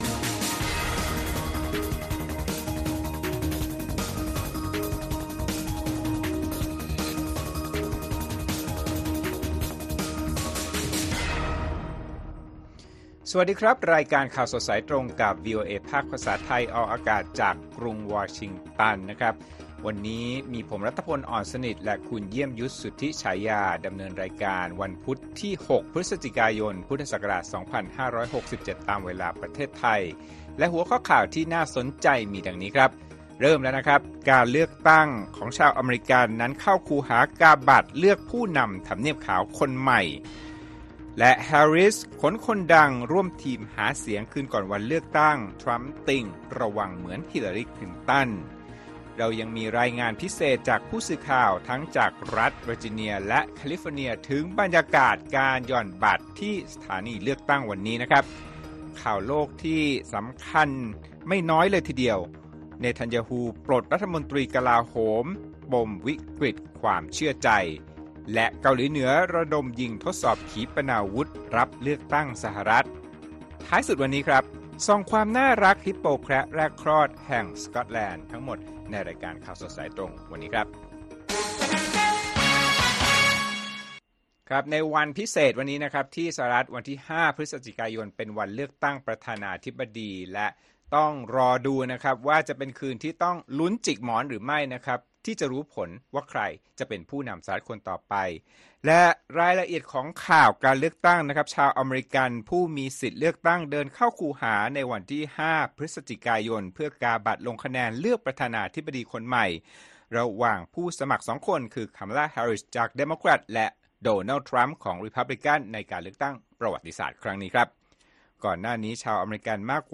ข่าวสดสายตรงจากวีโอเอ ไทย ประจำวันพุธที่ 6 พฤศจิกายน 2567